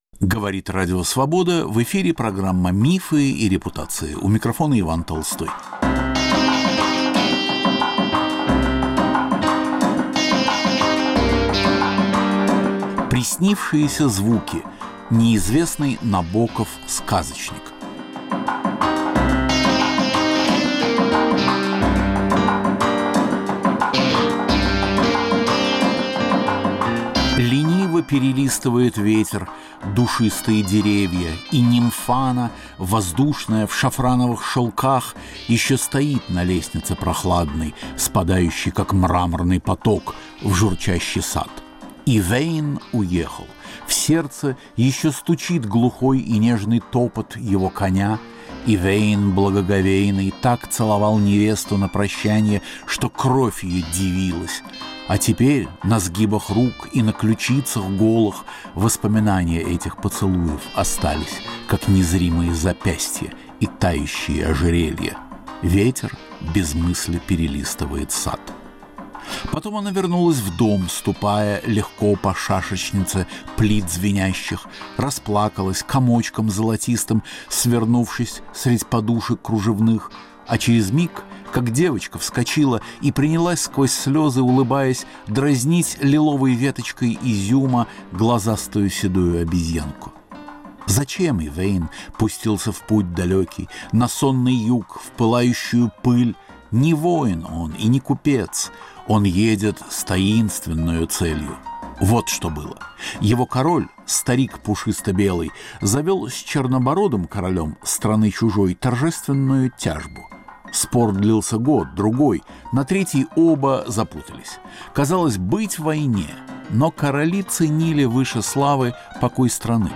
Приснившиеся звуки: Неизвестный Набоков-сказочник. Разговор о самом большом поэтическом произведении Владимира Набокова, хранившемся до сих пор в нью-йоркском архиве.